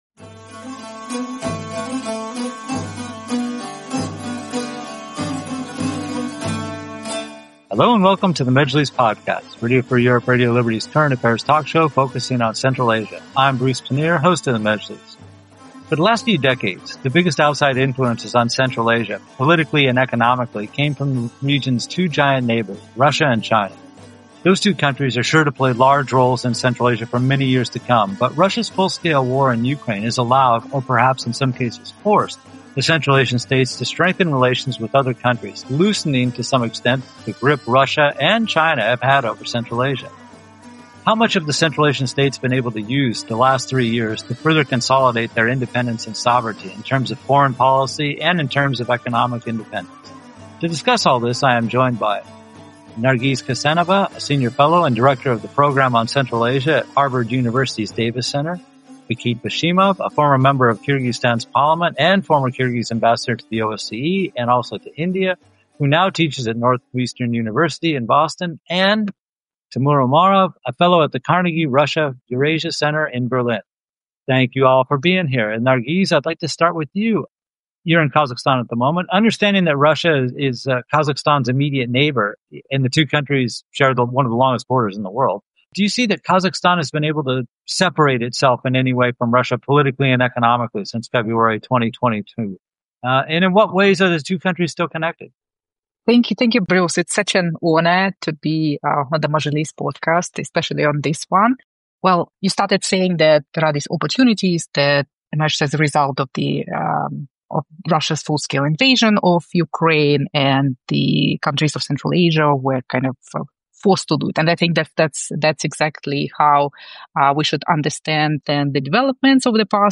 guests explore the shifting geopolitical landscape.